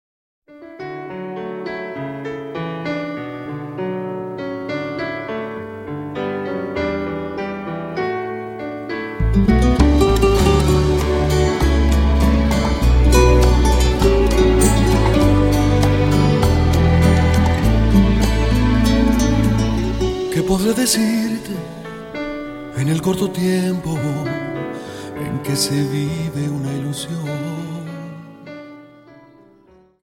Dance: Rumba 25 Song